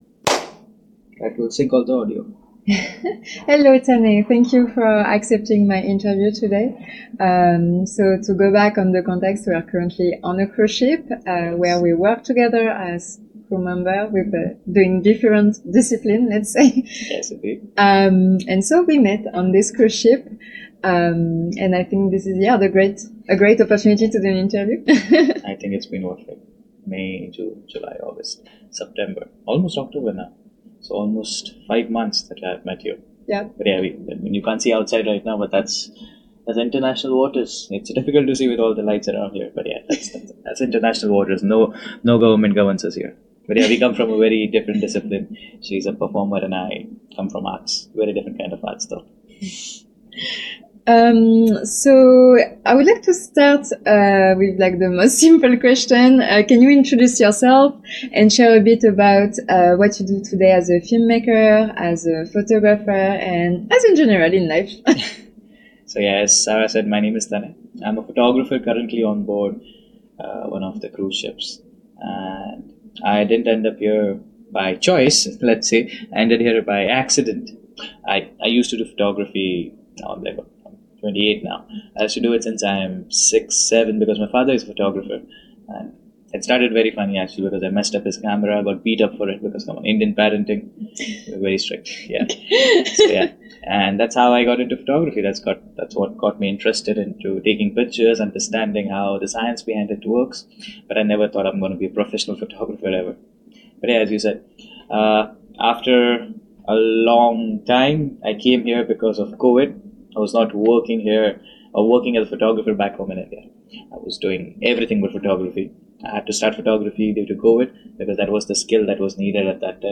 Wanderlust Ice & Ink - Interview